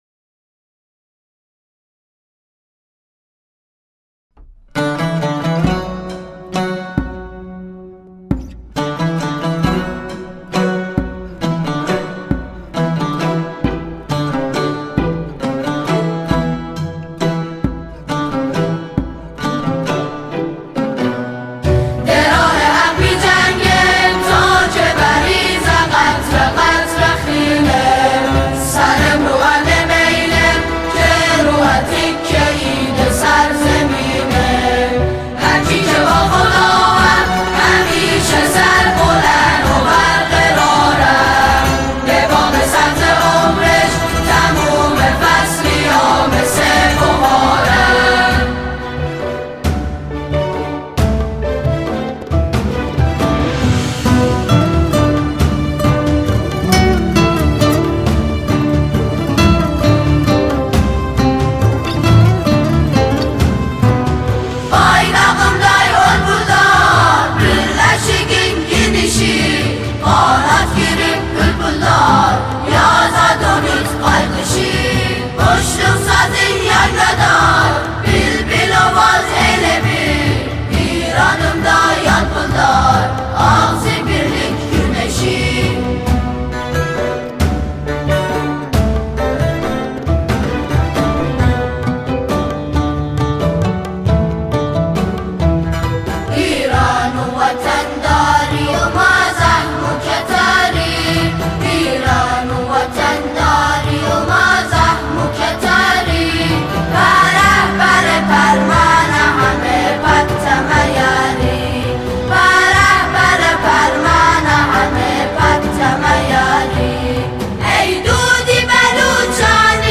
برگرفته از موسیقی فولکلور و زبان اقوام مختلف ایران زمین